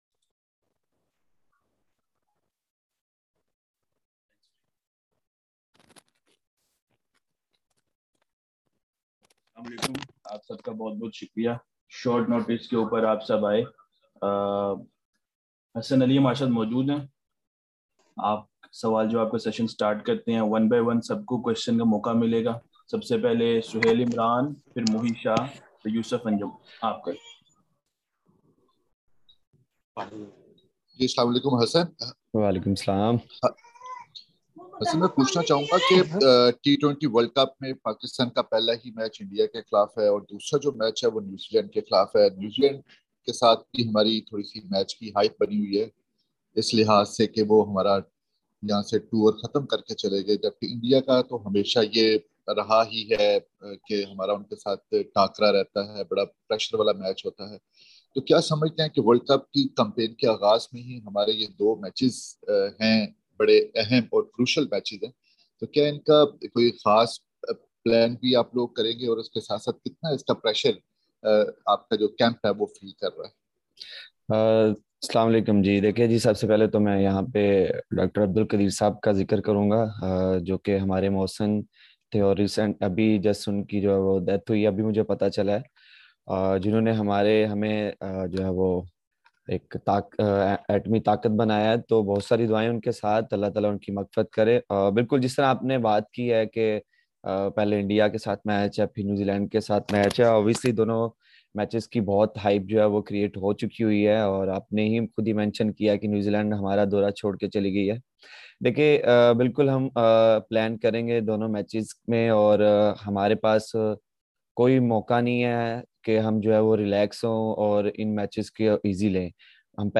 Hasan Ali’s Virtual Media Interview | ICC Men's T20 World Cup | PCB
Hasan Ali, the fast bowler of the ICC Men’s T20 World Cup 2021 bound Pakistan men’s national cricket team, interacted with media via videoconference call today in a virtual media session from Lahore.